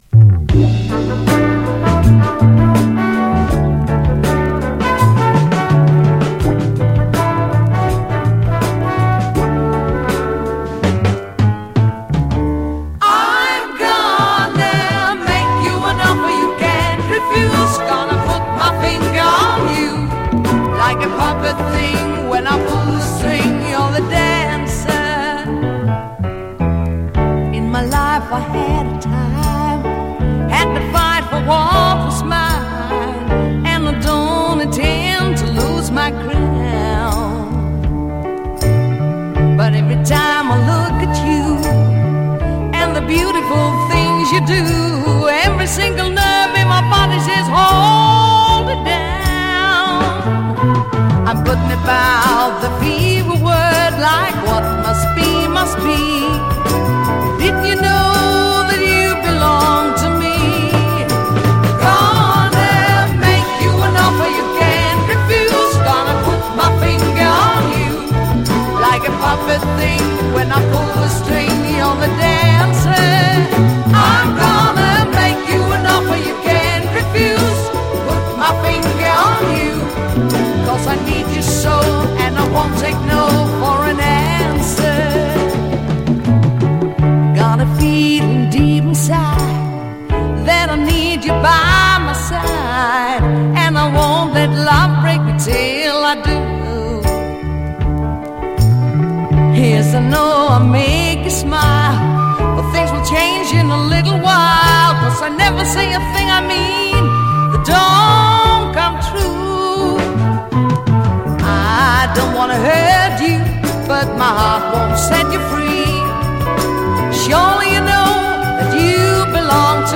絶品メロウ・カヴァー2曲
disk : EX- （曲間、静かな箇所で多少チリ音が出ます）